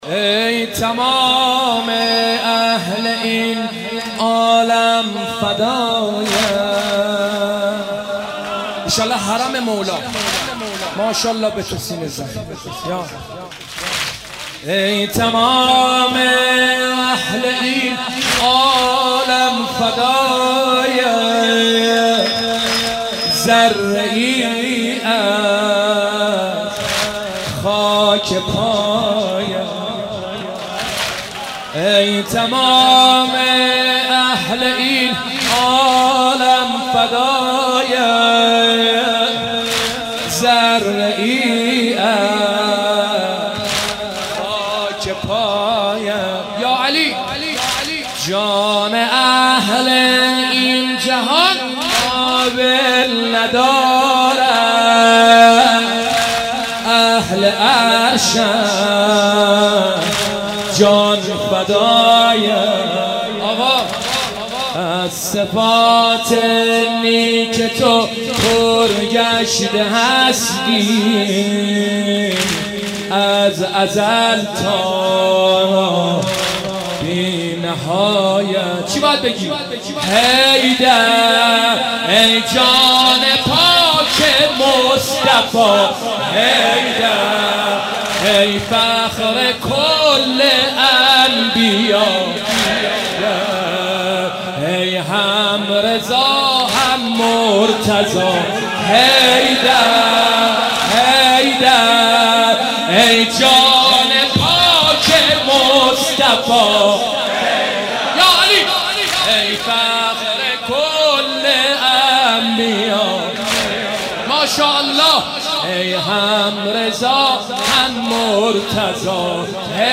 مناسبت : شب بیست و یکم رمضان - شب قدر دوم
قالب : سنگین